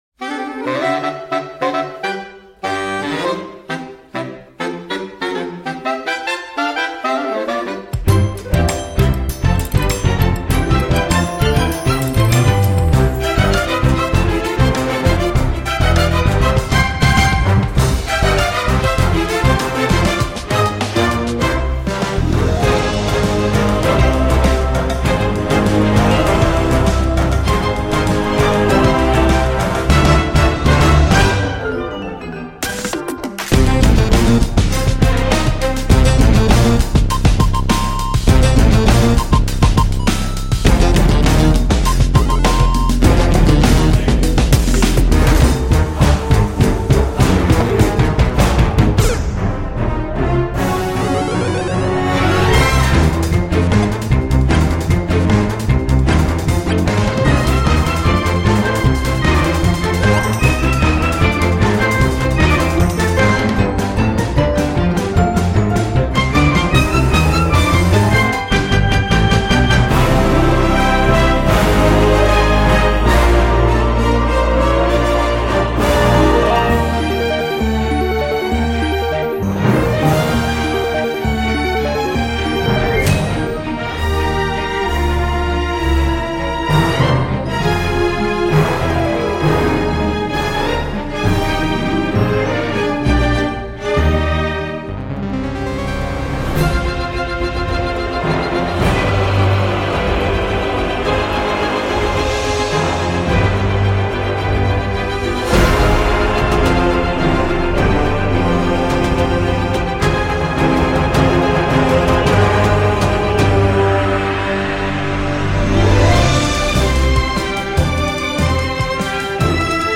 Drôle et fun, très orchestral.
Un peu long, pas révolutionnaire, mais plaisant.